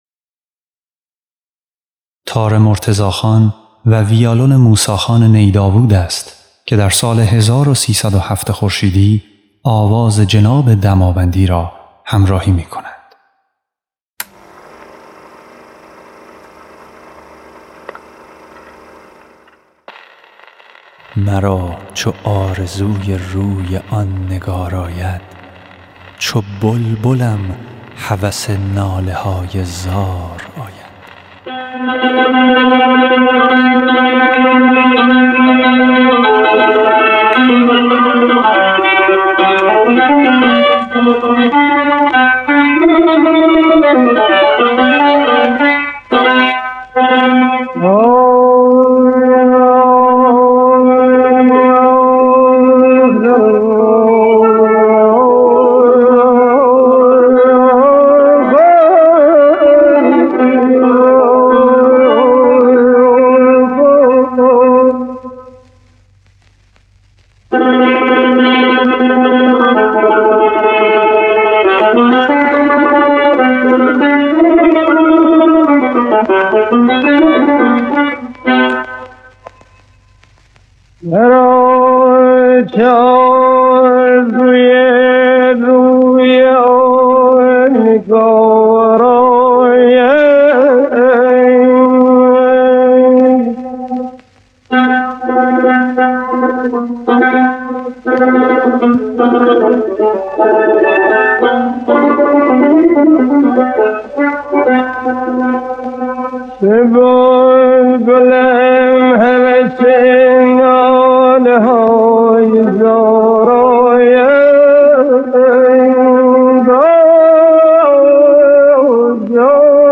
نوازنده تار
موسی نی داوود نوازنده ویالون
نوازنده ویولن
ضبط شده در سال 1307 خورشیدی